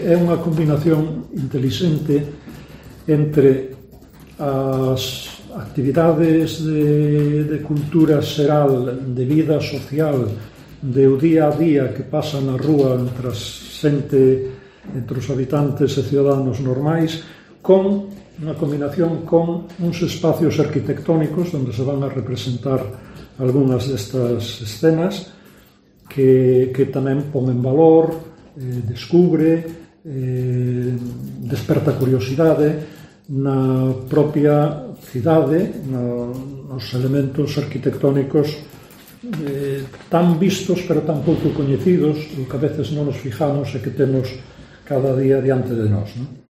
José Antonio Ponte Far, concejal de cultura de Ferrol